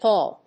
/pˈɔːl(米国英語)/